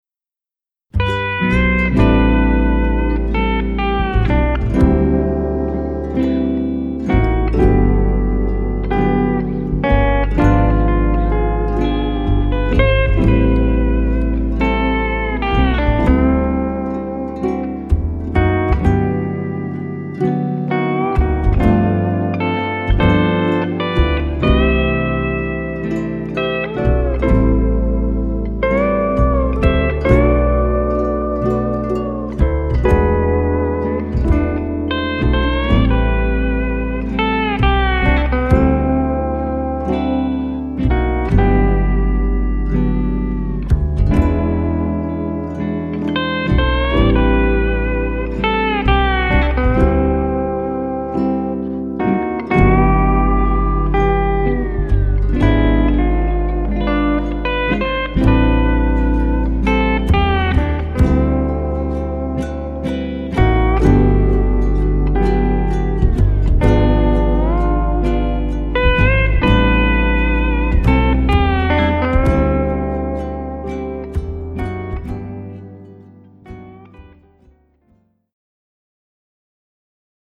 I am looking forward to playing my Steel Guitar at a wedding at the Kea Lani tomorrow.
After listening to Kealii Reichel’s version and especially  Nathan Aweau’s amazing version, I decided to spice it up a little.
It sounds a little thin. I played it through my Fender Concert amp.